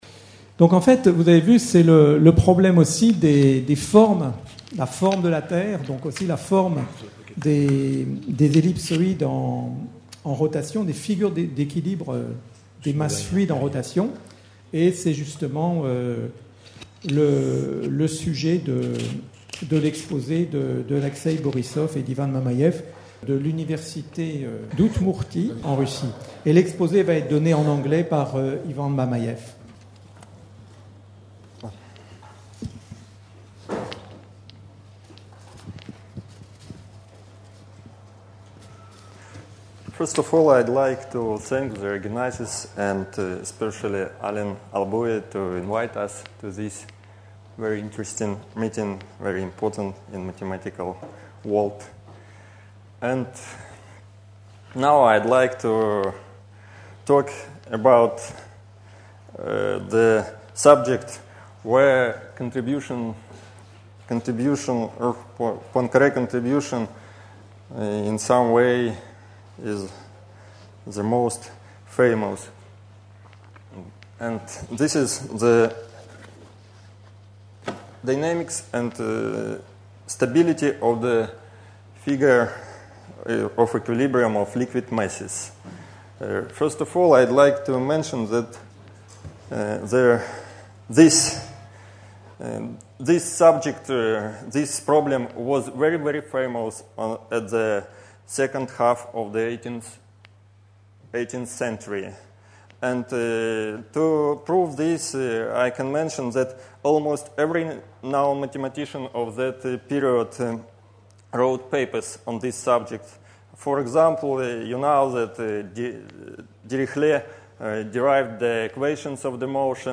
Journée de commémoration du centenaire de la disparition de Henri Poincaré (29 avril 1854 - 17 juillet 1912), organisée à l'Institut d'Astrophysique de Paris le9 Juillet 2012.